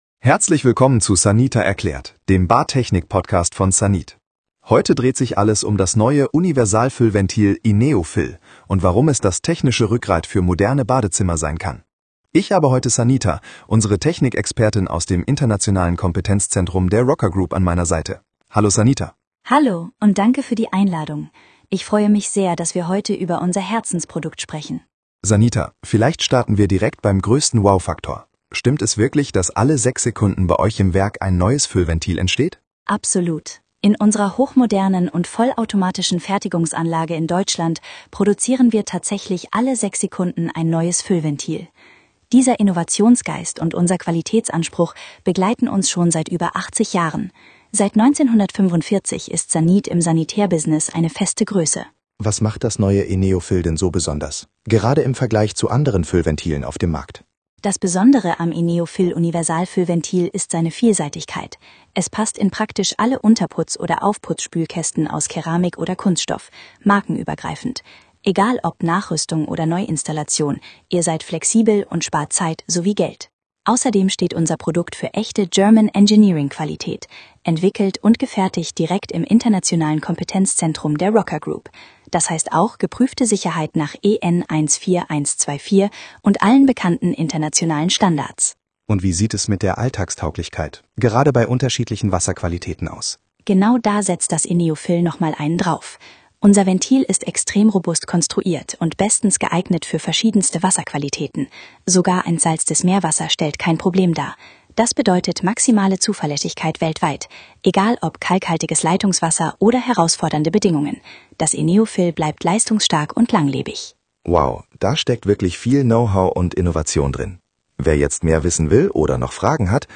Flow-Technik-–-Der-SANIT-Experten-Talk-E1-INEO-FILL-Fuellventil.mp3